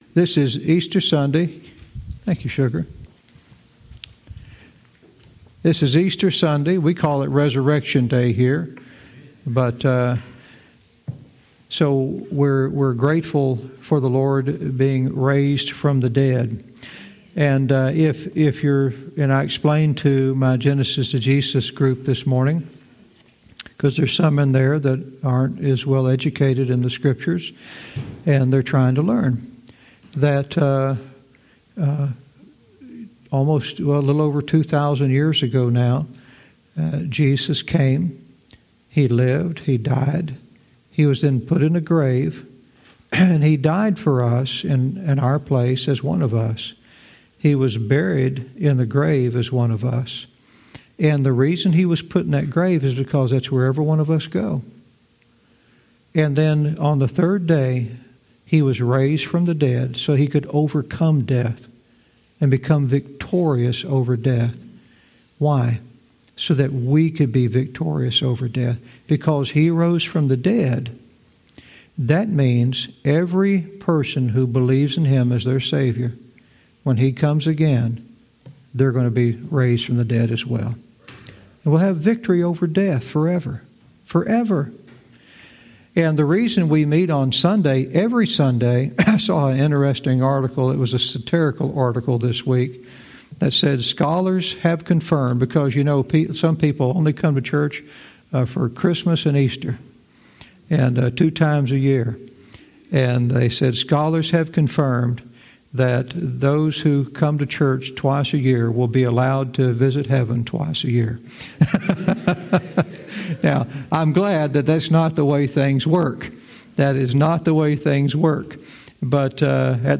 Verse by verse teaching - Daniel 5:18-22 "You Knew Better"